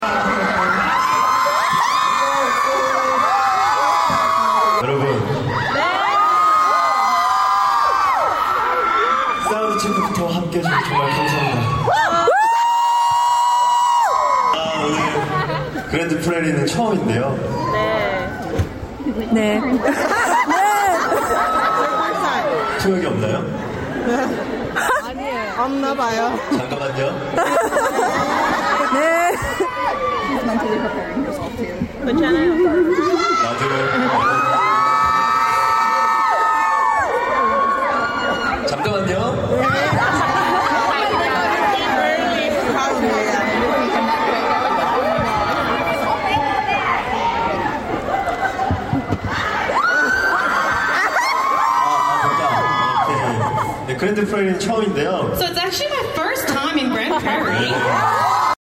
Texas Trust CU Theatre in Grand Prairie, Dallas.